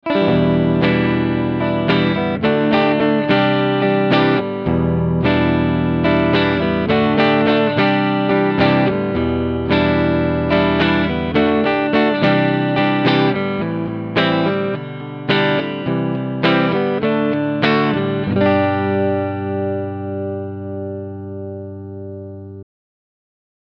Die Roswell LAF 5 Humbucker liefern in Kombination mit dem Mahagoni Holz sehr schöne Sounds.
Harley Benton EX-76 Classic GHW AN Soundbeispiele
Ich habe für alle Beispiele meinen Mesa Boogie Mark V 25 mit dem CabClone D.I. verwendet.